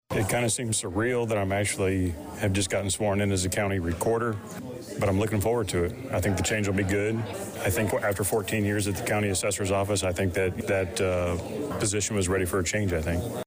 On the fourth floor of the Vermilion County Courthouse Monday (Dec 2nd) morning, five county office holders took the oath for new terms in office.  Matthew Long, elected to the office of Recorder of Deeds on November 5th, and moving over from the Supervisor of Assessments position, said afterwards it was the end of a long road, and also a new beginning.